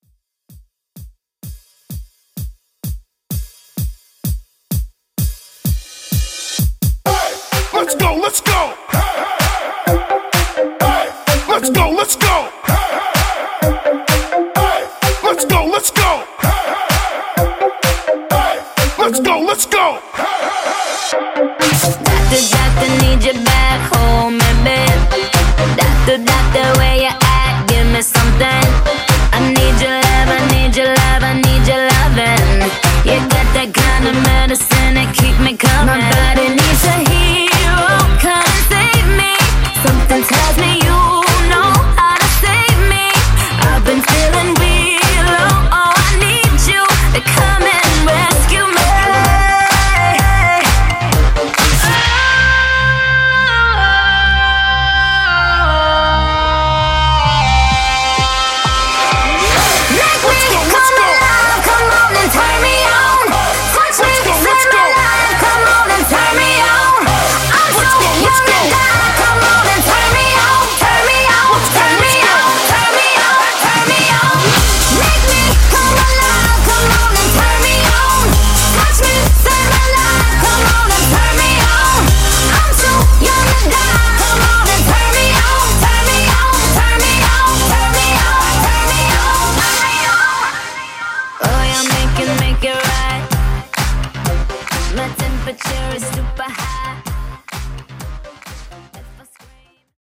Intro House